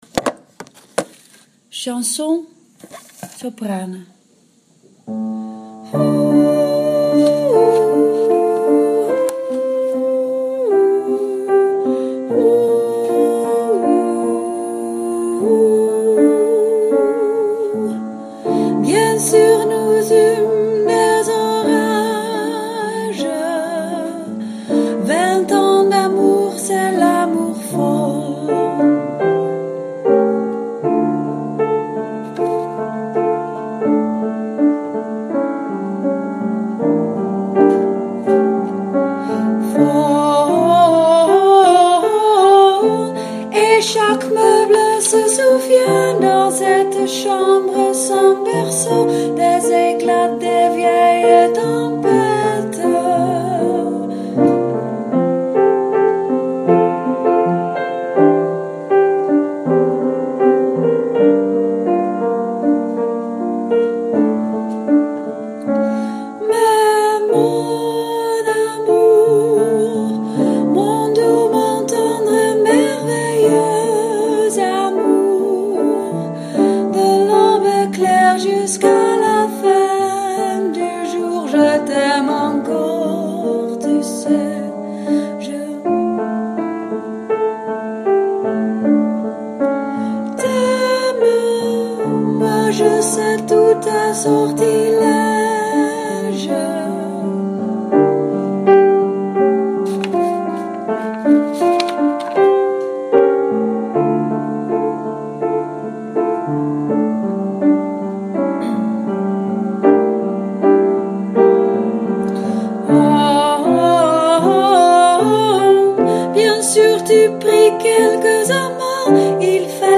Chanson-Sopranen.mp3